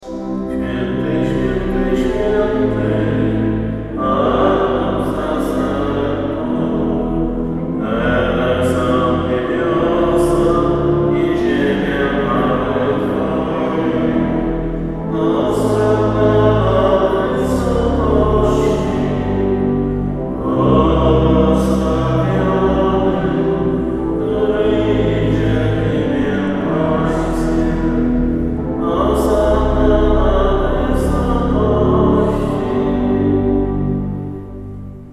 Index of /kosciol_sw._Aleksandra/Msza poniedziałek